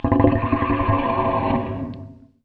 MONSTERS_CREATURES
MONSTER_Noise_08_mono.wav